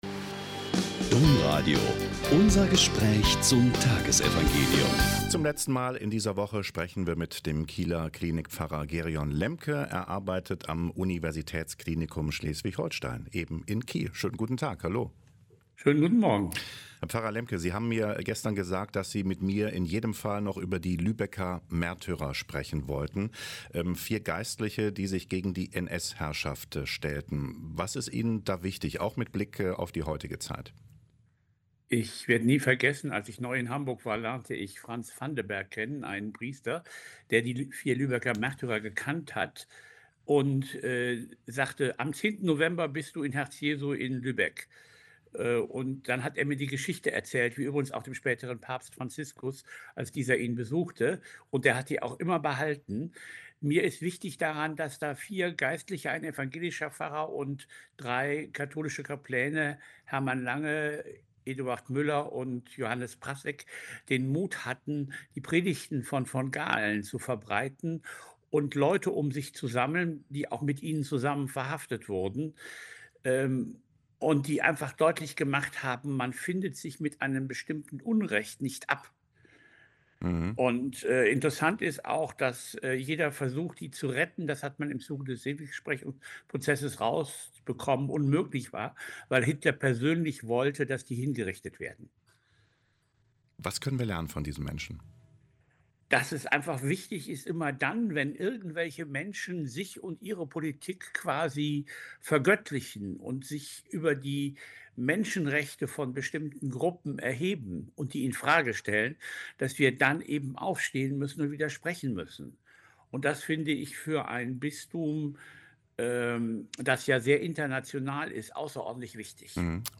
Joh 14,7-14 - Gespräch